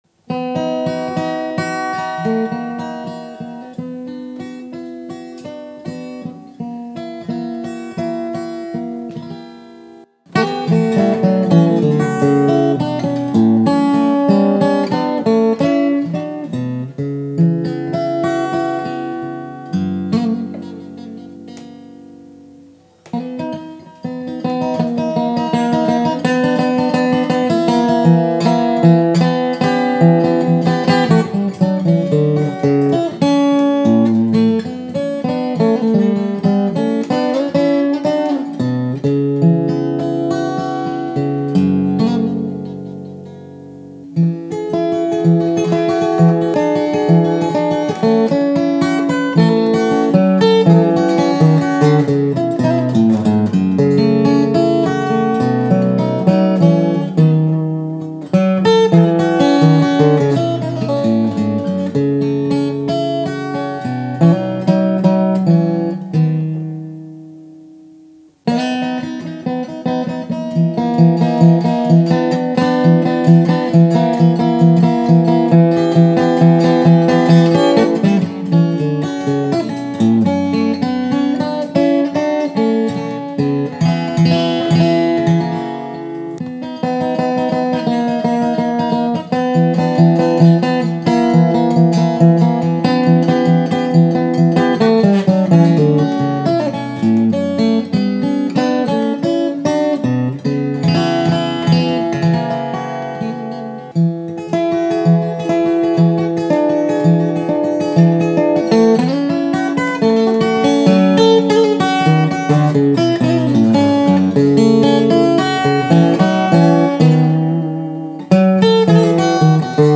A lengthy piece that combines my guitar solo ‘Swifts’ with my setting of a poem by W.B. Yeats – ‘The Wild Swans at Coole’.
For this more recent version, the guitar sounds better but it’s not the best I’ve ever sung it.
Instrumental